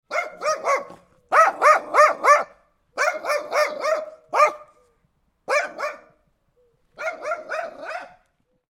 Guard Dog Alert Barking Sound Effect
Description: Guard dog alert barking sound effect.
Guard-dog-alert-barking-sound-effect.mp3